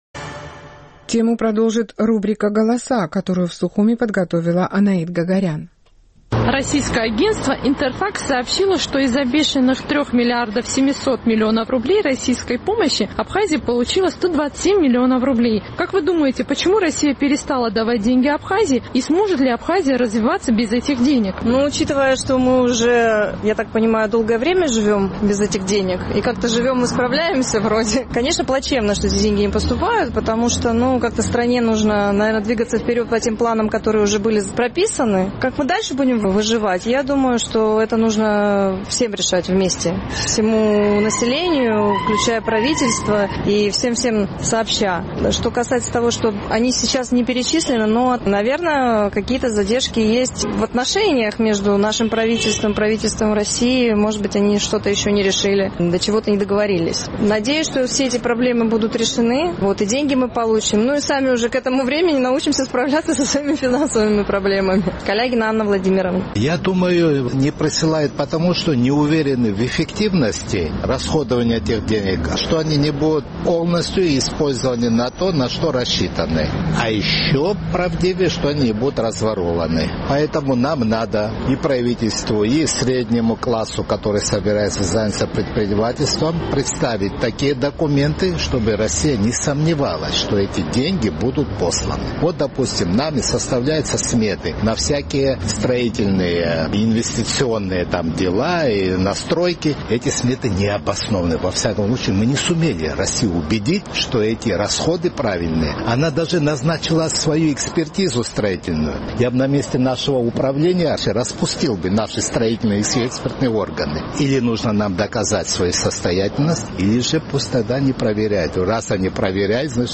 Наш сухумский корреспондент поинтересовался мнением жителей абхазской столицы по поводу того, сможет ли республика развиваться без российской финансовой помощи.